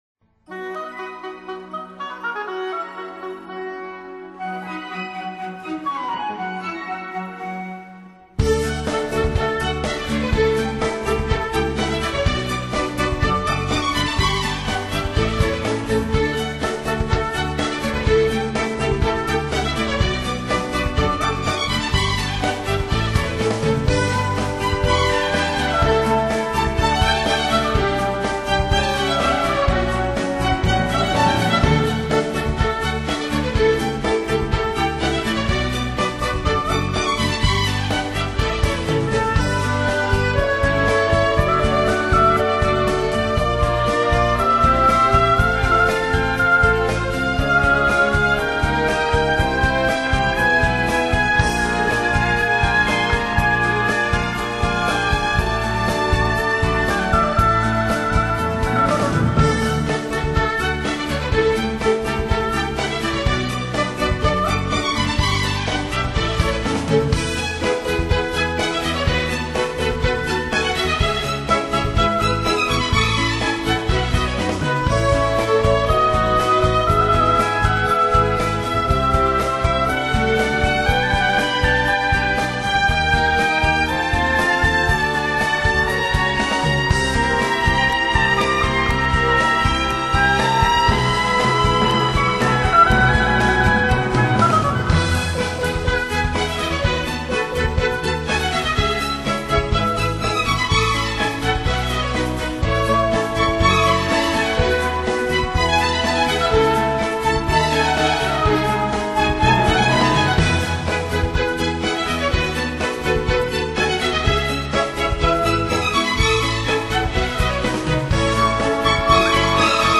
专辑语言：纯音乐
类型：NewAge